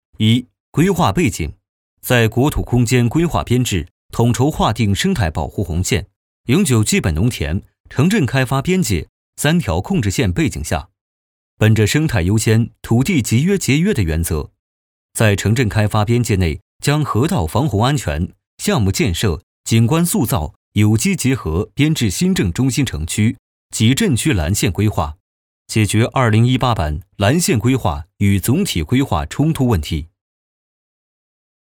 男10号